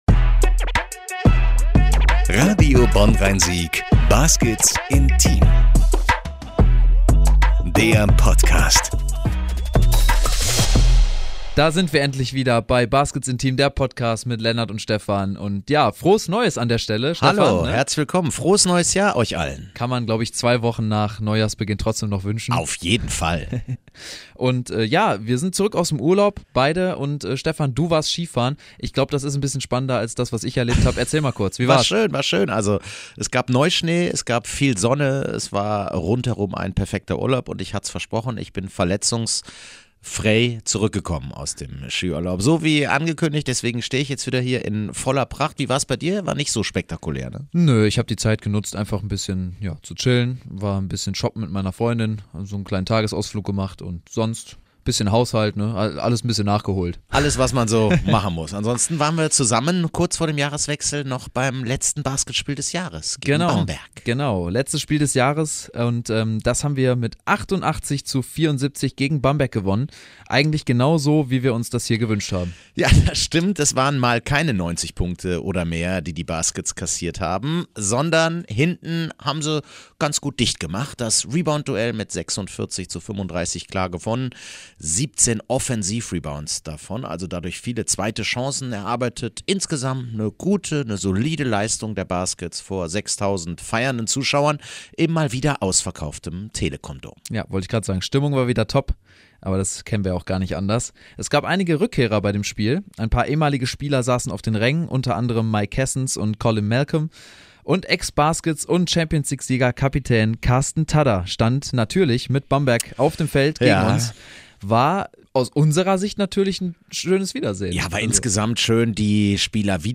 Noch mehr Fans, noch mehr Erinnerungen an 2023 und Wünsche für 2024, hört ihr in der neuen Folge.